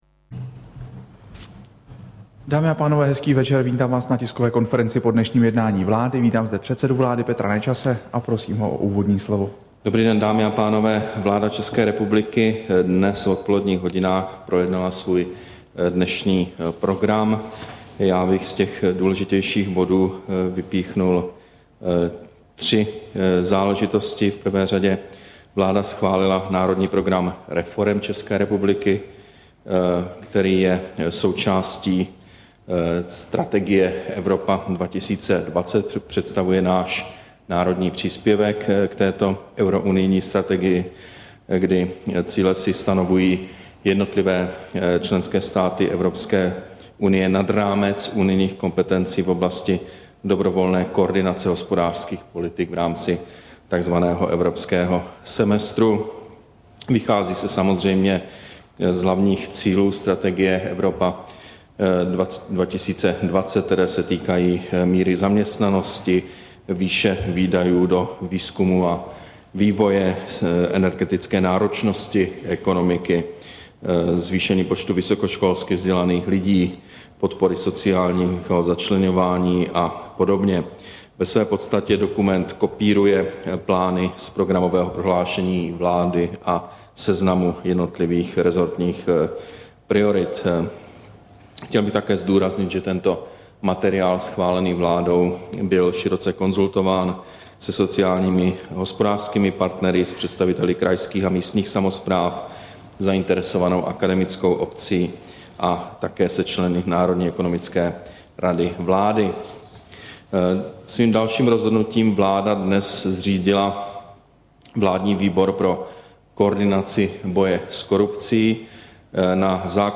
Tisková konference po jednání vlády, 27. dubna 2011